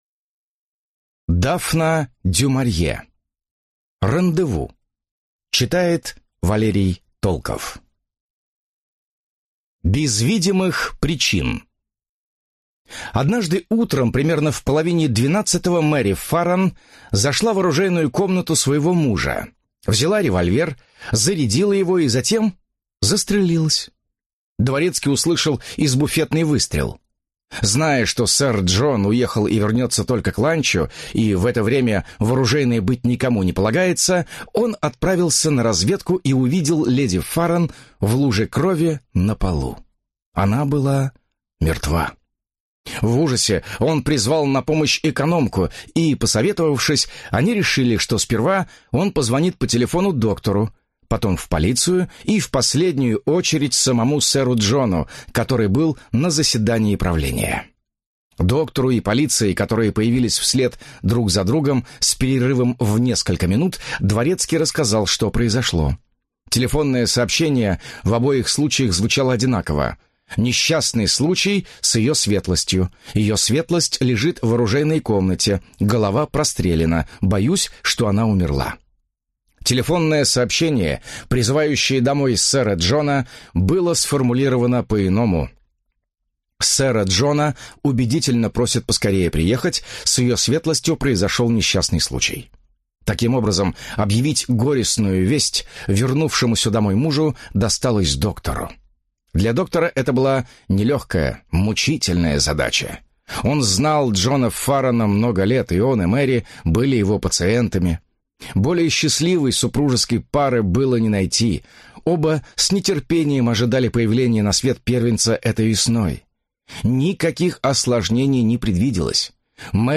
Аудиокнига Рандеву | Библиотека аудиокниг